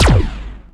fire_gun1_rank1.wav